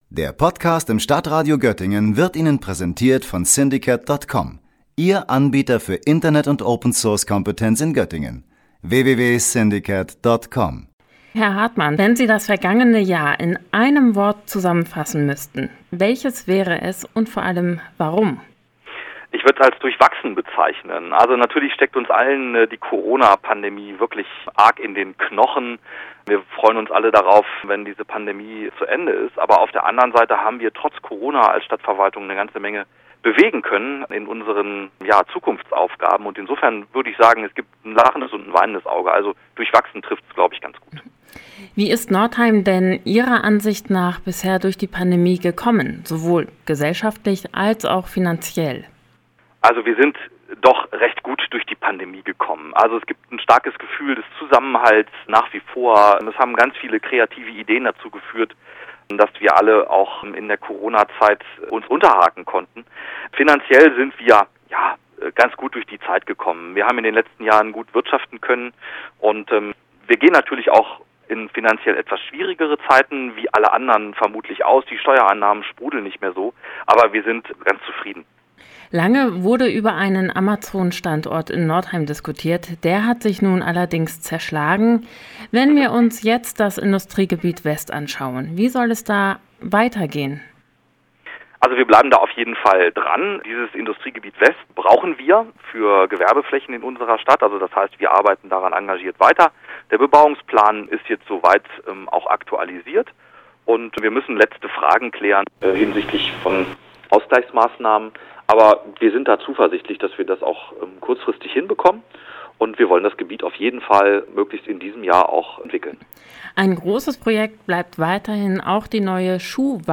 Beiträge > Jahresrückblick - Jahresausblick: Northeims Bürgermeister Simon Hartmann im Interview - StadtRadio Göttingen